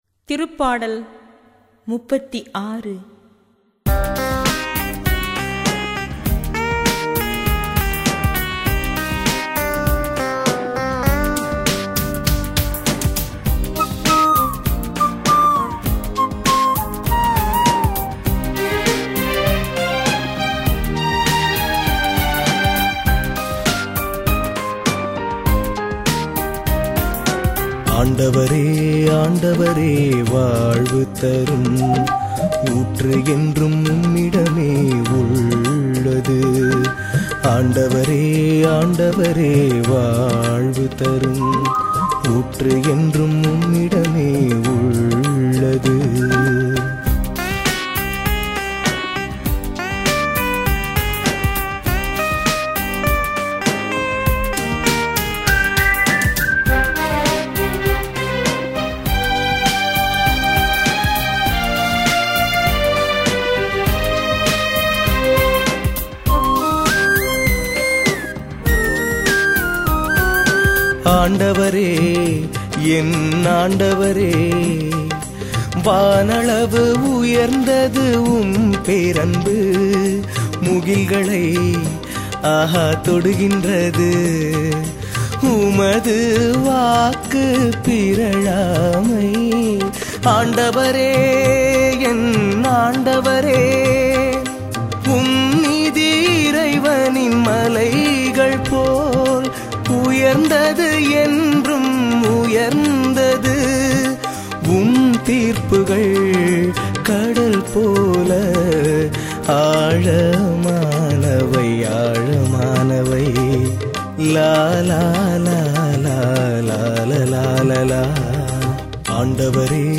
பதிலுரைப் பாடல் -